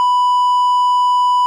Noheartrate.wav